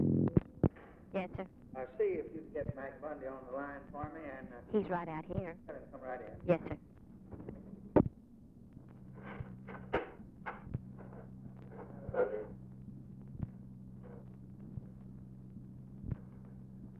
Conversation with OFFICE SECRETARY, November 29, 1963
Secret White House Tapes